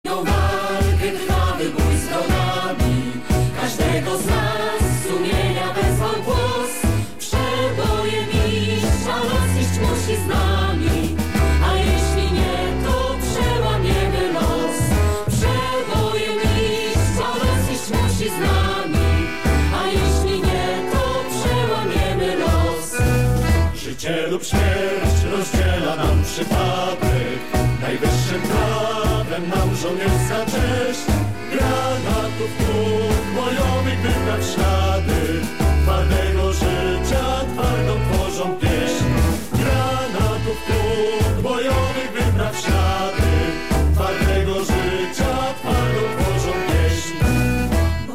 co to za piosenka z radia katowice?
To jest patriotyczna pieśń pt. "Na znojną walkę"/ "Przebojem iść"